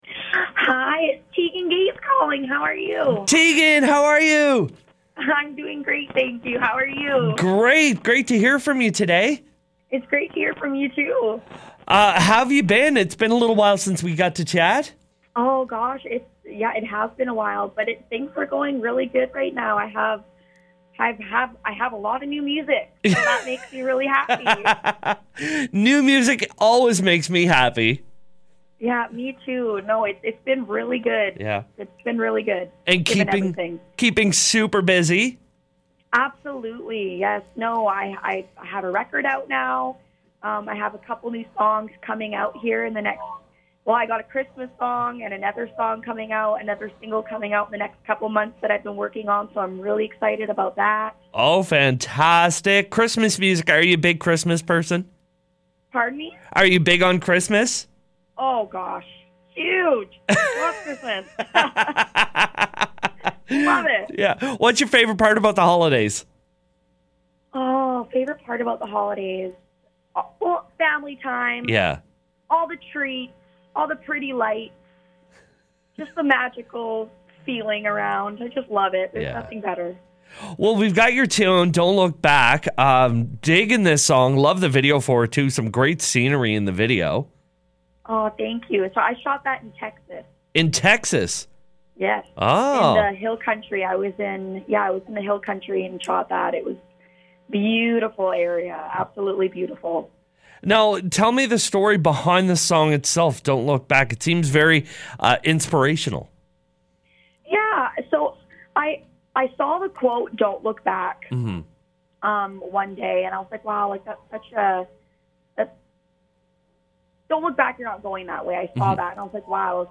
full chat